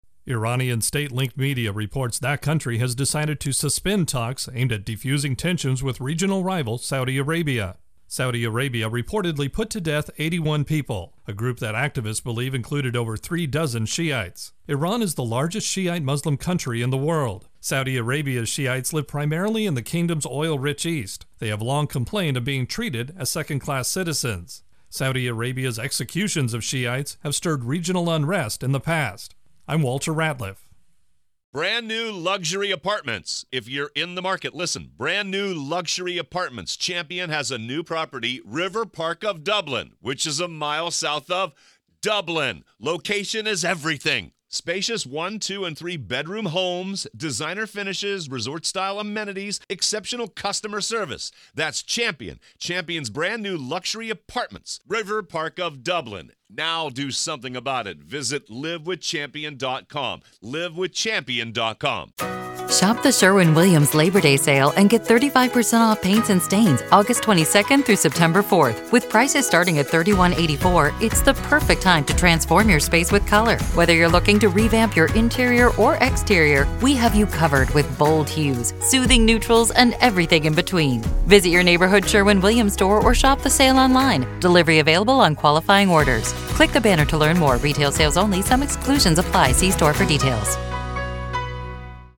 Intro and voicer for Iran Saudi